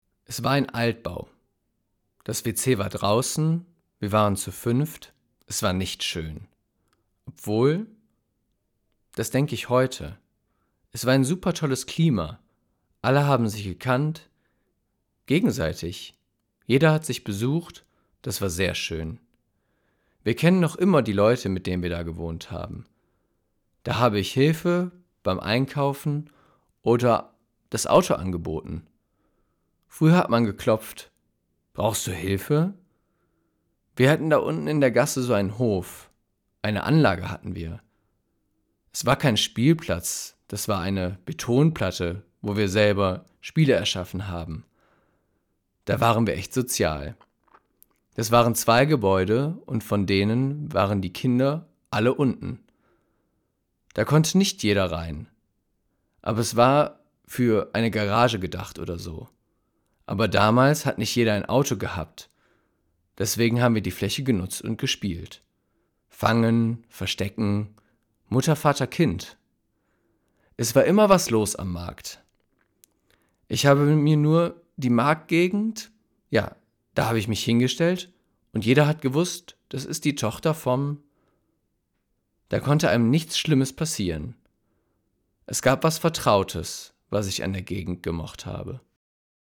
Für die Website wurden die Textstellen nachgelesen: Einige von Jugendlichen und jungen Erwachsenen aus den Wohnhausanlagen, andere von Mitgliedern des Projektteams oder ausgebildeten Schauspielern.